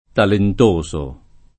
talent1So] agg. — solo così, come arcaismo per «desideroso» (in poeti del ’2-300) — come francesismo per «ingegnoso» (in scrittori dell’8-900), spesso nella forma (alla fr.) talentuoso [talentu-1So]